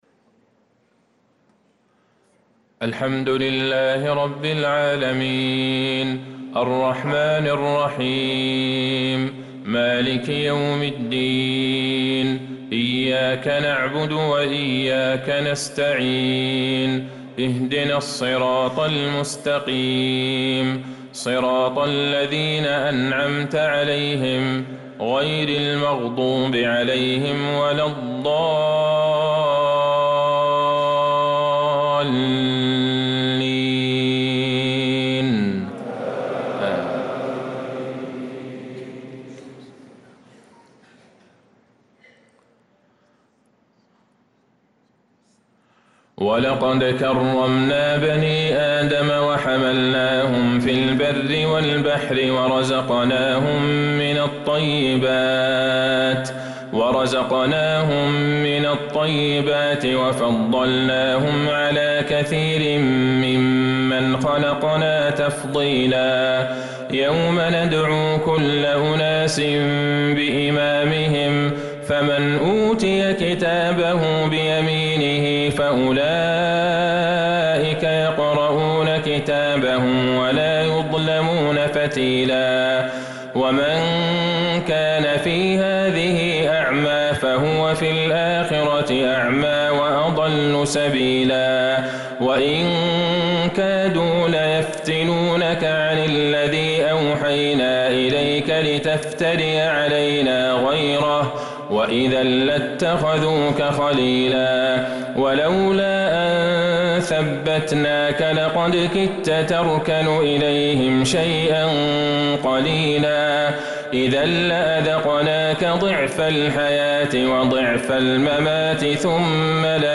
صلاة العشاء للقارئ عبدالله البعيجان 22 ربيع الآخر 1446 هـ
تِلَاوَات الْحَرَمَيْن .